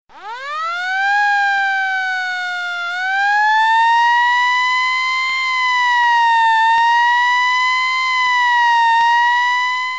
P O L I C E
pozharnaia-mashina_24645.mp3